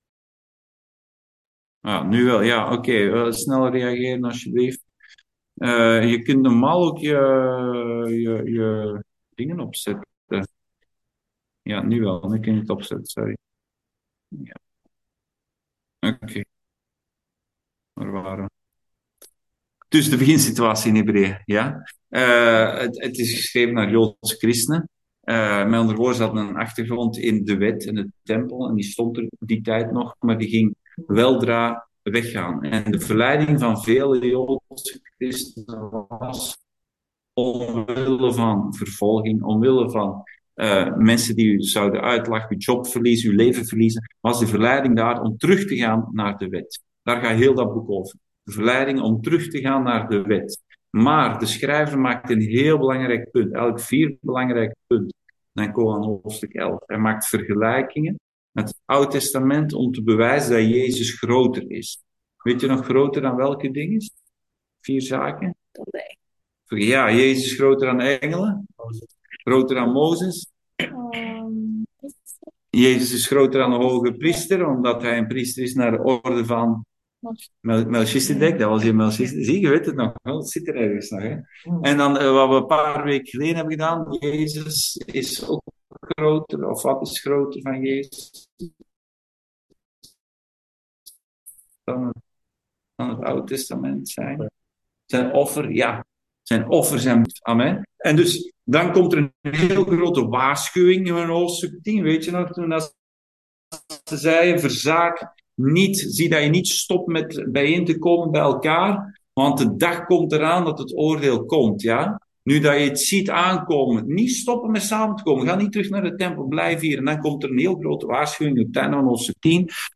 Dienstsoort: Bijbelstudie